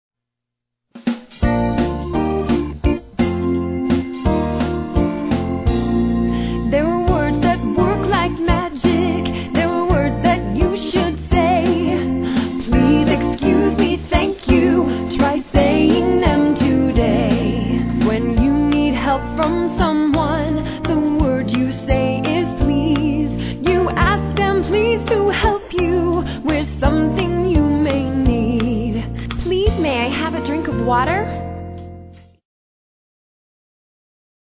Educational Songs by Subject